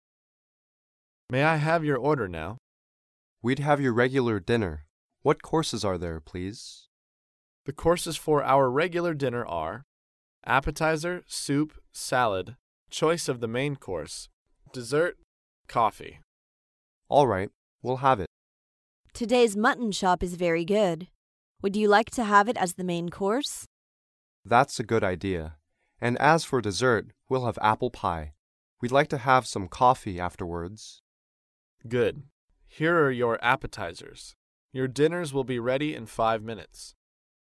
高频英语口语对话 第219:西餐点菜(9) 听力文件下载—在线英语听力室